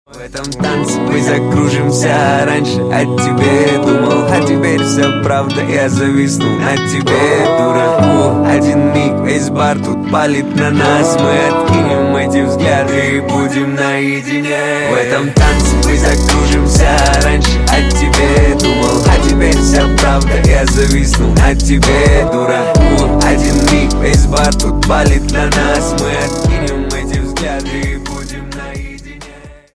Рэп Хип-Хоп Рингтоны
Скачать припев песни